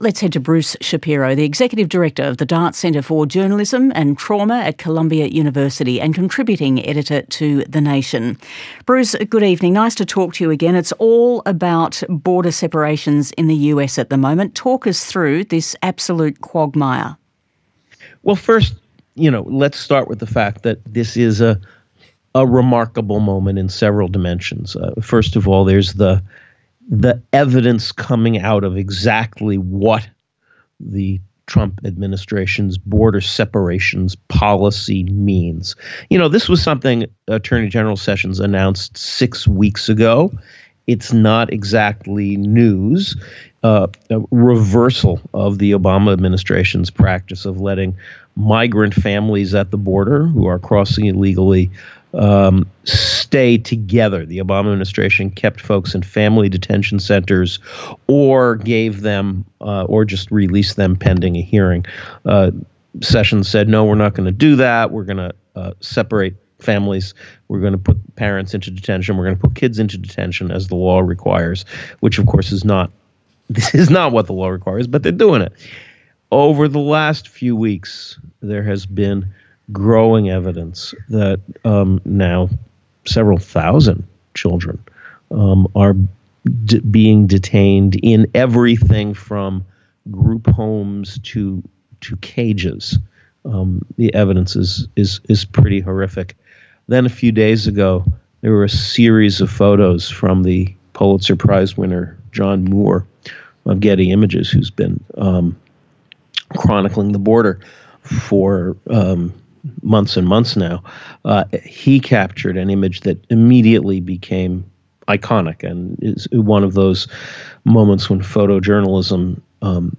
June 18-20 - The World Watches - The World Weighs In - news reports from Overseas over the recent activities in the U.S. immigration crisis.
And for proof of that, I’ve been doing some Stream-hopping overseas, grabbing broadcasts from Australia, Canada, the UK and the English Service of Radio France International just to get some flavor of the feelings towards this series of events with refugees – how they were feeling about all that in Europe and the rest of the world.